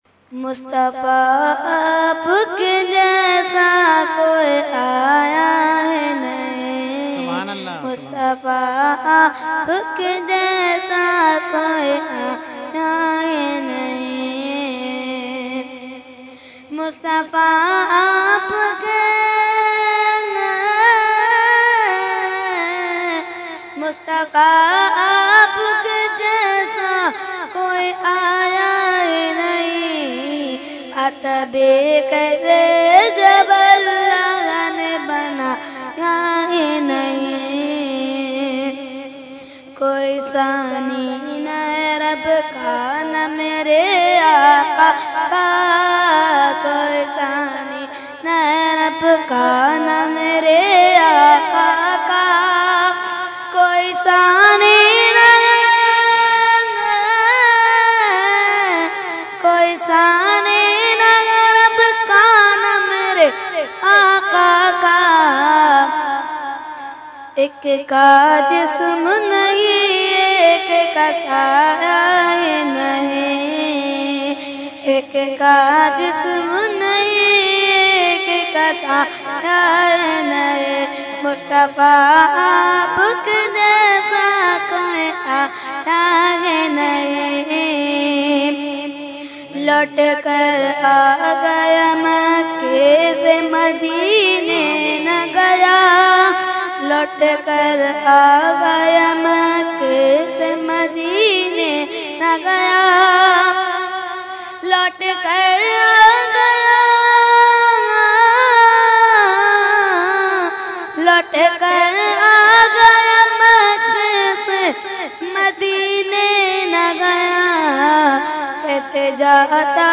Mediaa: Urs Ashraful Mashaikh 2015
Category : Naat | Language : UrduEvent : Urs Ashraful Mashaikh 2015